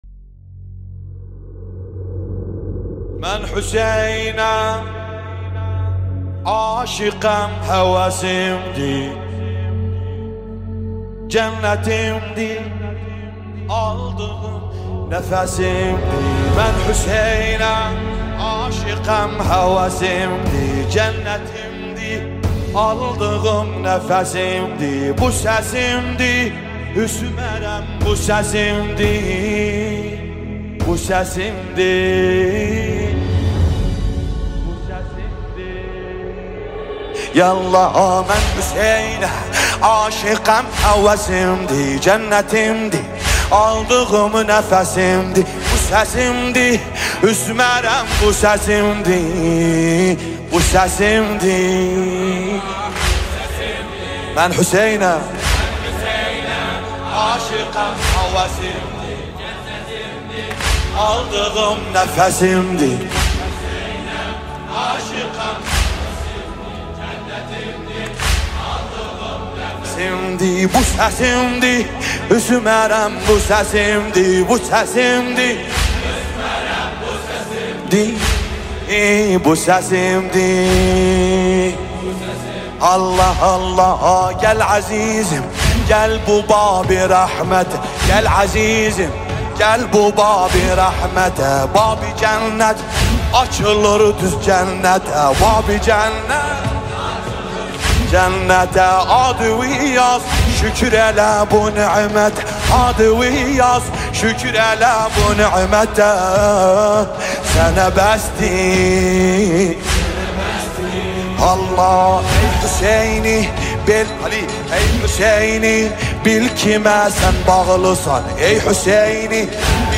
نوحه ترکی عراقی